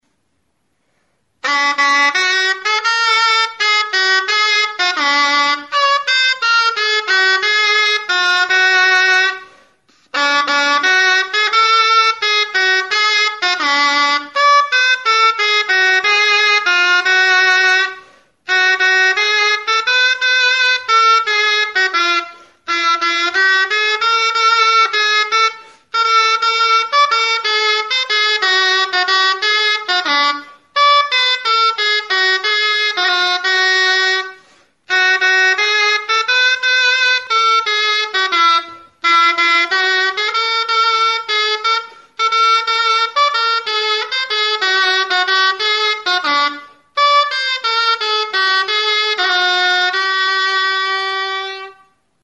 Music instrumentsOBOE
Aerophones -> Reeds -> Double (oboe)
Recorded with this music instrument.
12 giltza dituen XIX. mendeko oboea da.